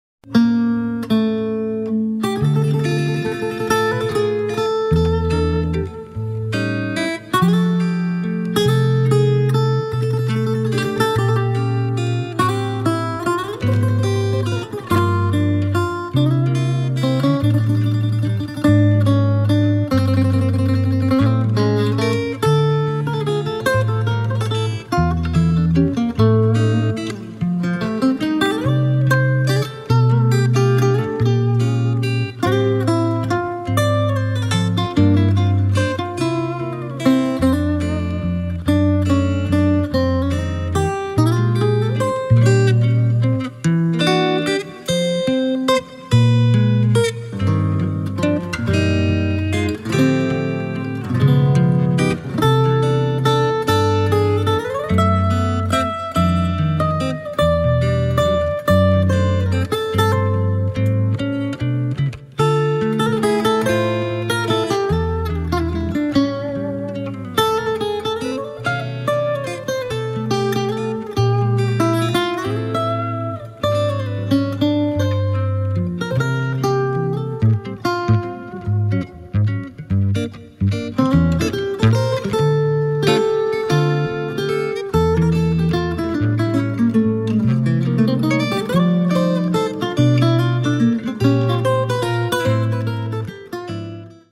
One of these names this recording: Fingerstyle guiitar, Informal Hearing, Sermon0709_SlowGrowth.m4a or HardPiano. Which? Fingerstyle guiitar